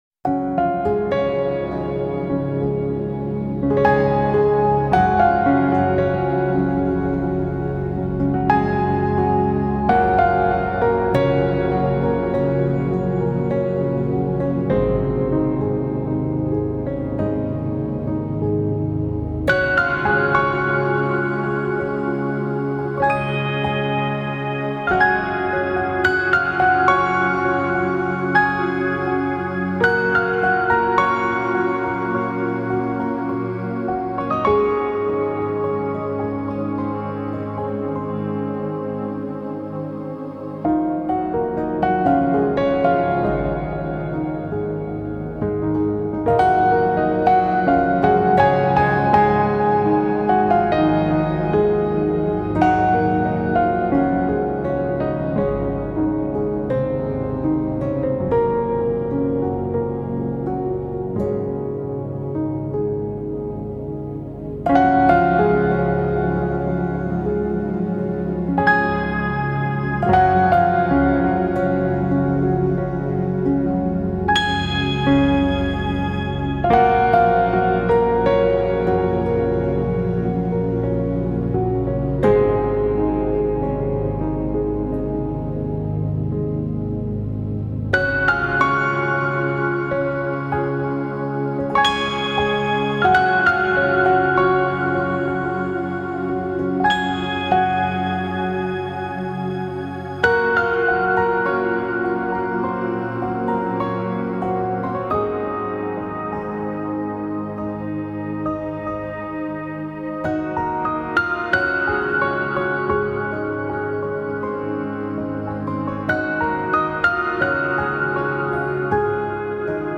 light music
نوع آهنگ: لایت]